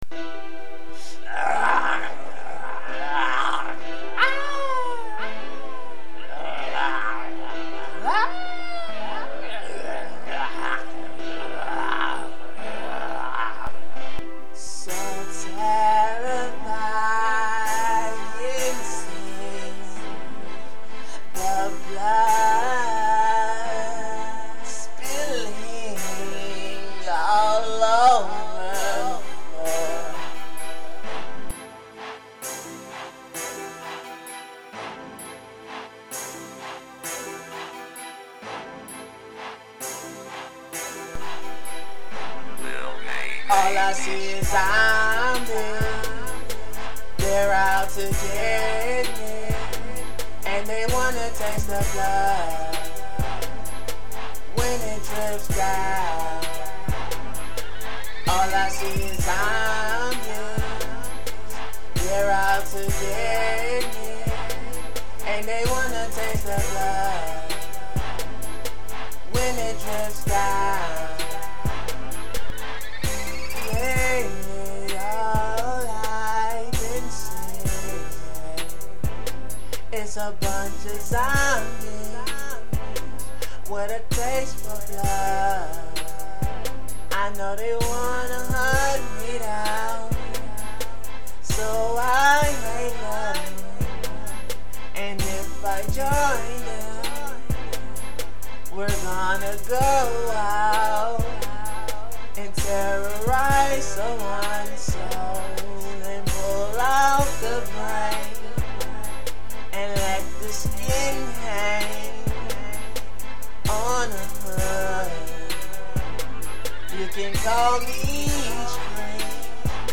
Hiphop
This is a horror song